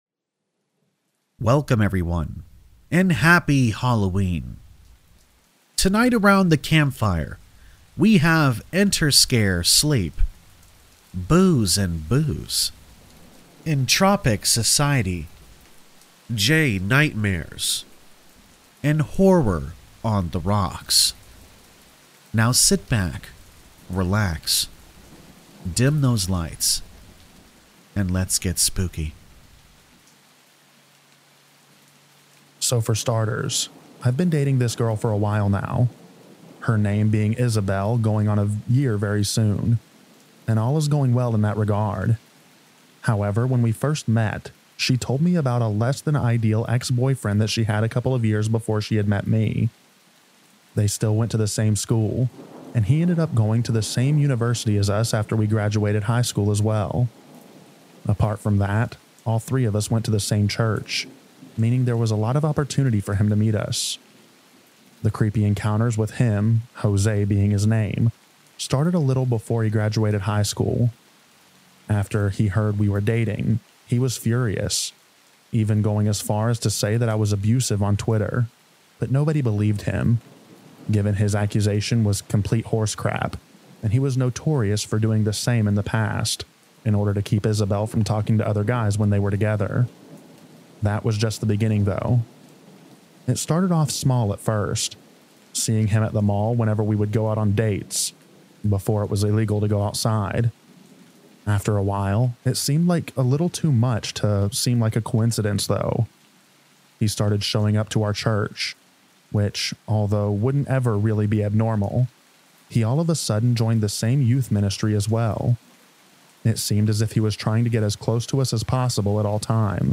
All Stories are read with full permission from the authors: Story Credits - 1.